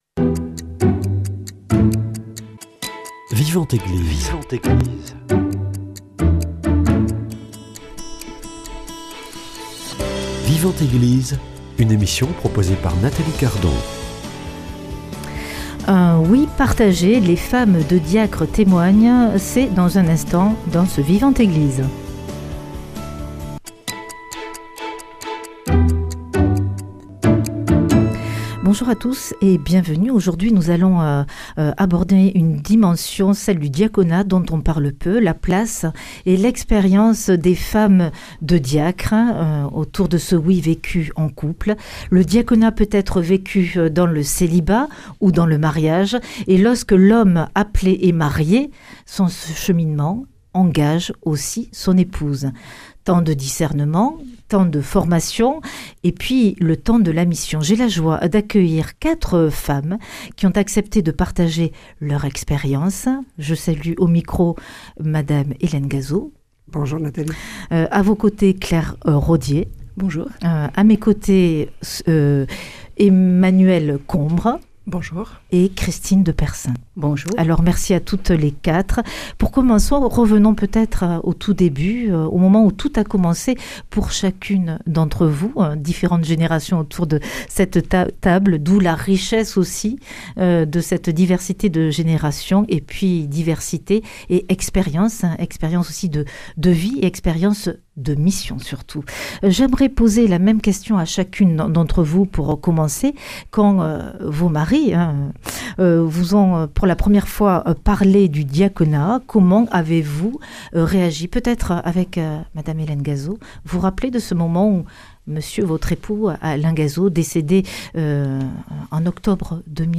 Aujourd’hui dans Vivante Église, nous mettons à l’honneur les femmes de diacres. Elles viennent partager leurs témoignages, leurs expériences, et le rôle qu’elles jouent au sein de la vie de l’Église.